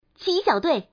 Index of /client/common_mahjong_tianjin/mahjongwuqing/update/1161/res/sfx/changsha/woman/